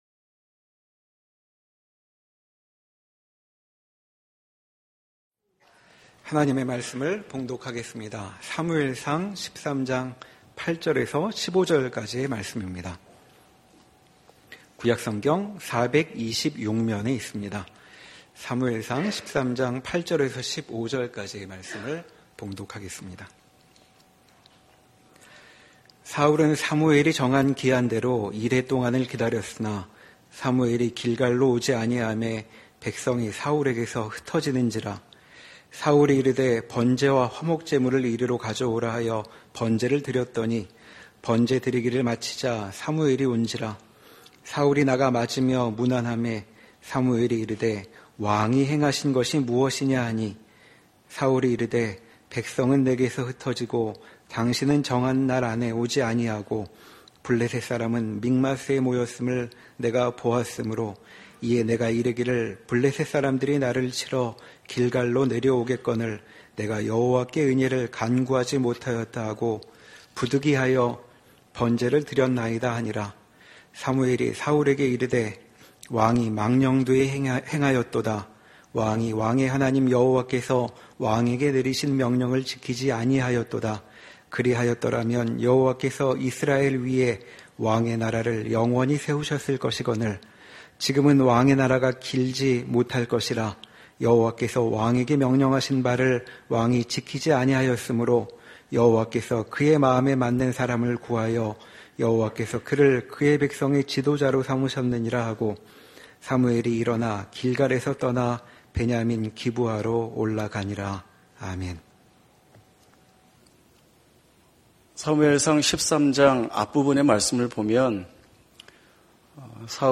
사무엘상 강해 18
주일예배